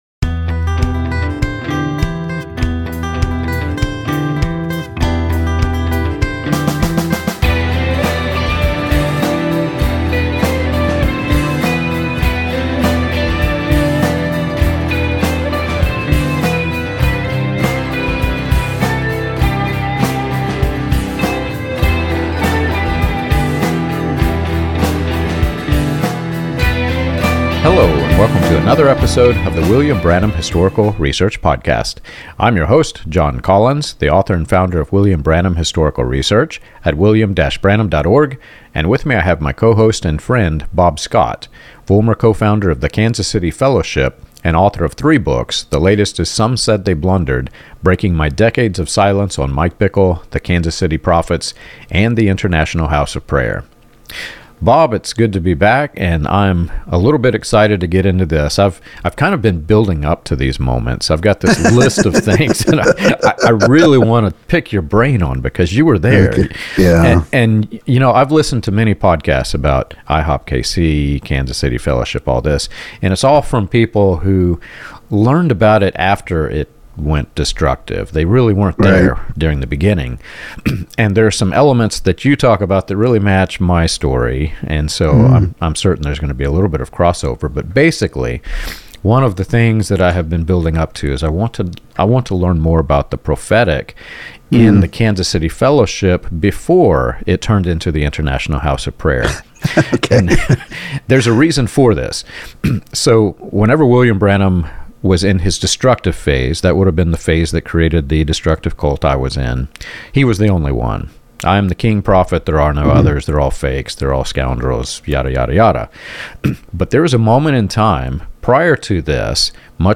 The conversation explores the historical through-line from William Branham and the Latter Rain to contemporary apostolic movements, showing how prophecy became systemic rather than spiritual.